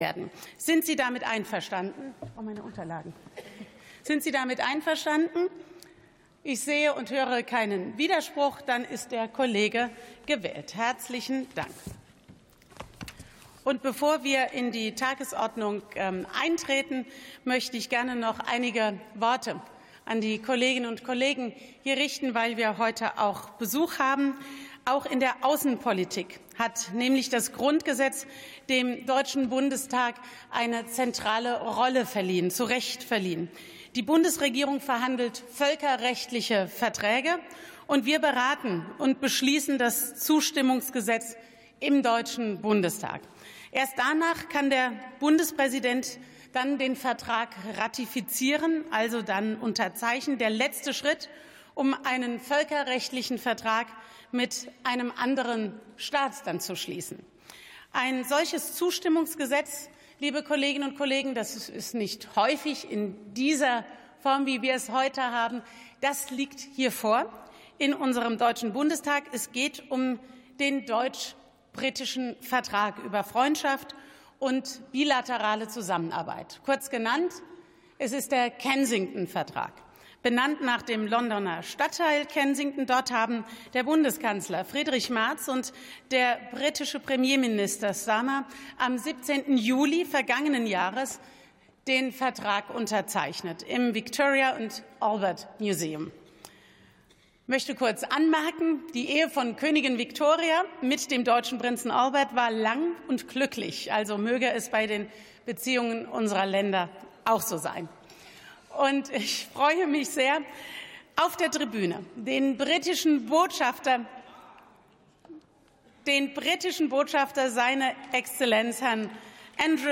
62. Sitzung vom 05.03.2026. TOP Sitzungseröffnung: